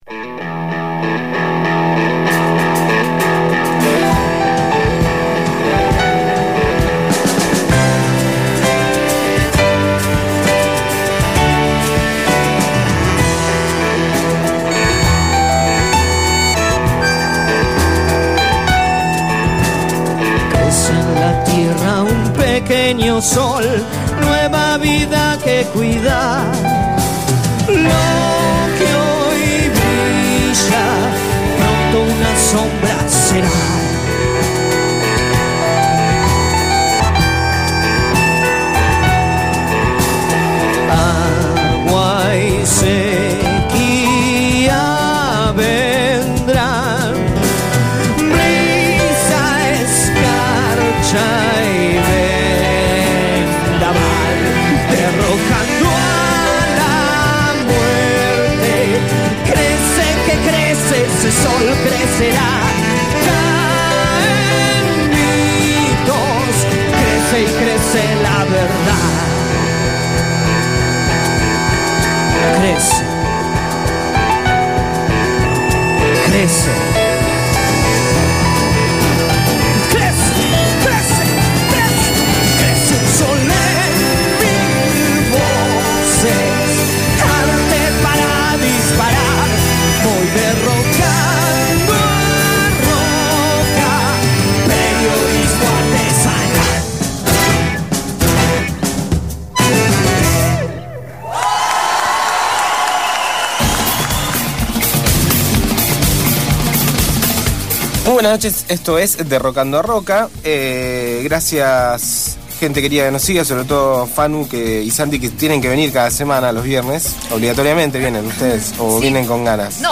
Nos comunicamos telefónicamente con Alejandro Vilca (PTS) que fue candidato a diputado nacional y provincial en Jujuy.